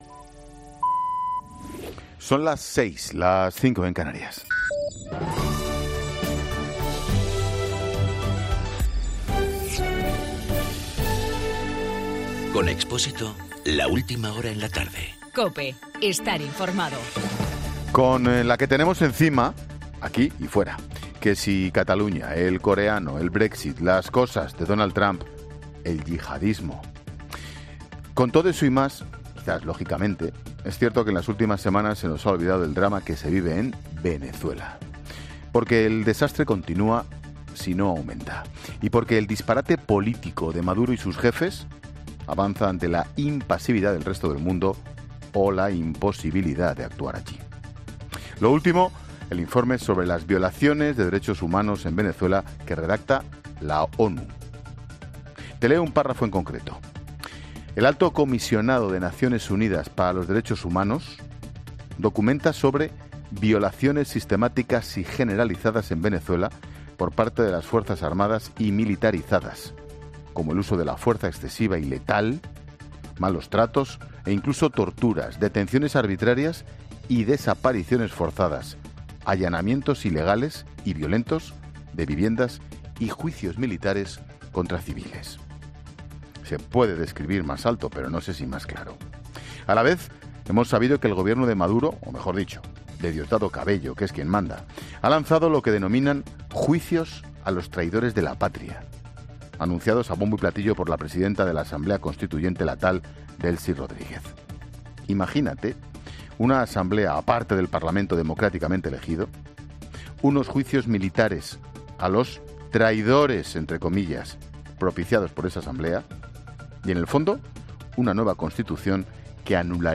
AUDIO: Monólogo de Ángel Expósito a las 18h, sobre las violaciones de derechos humanos en Venezuela según la ONU.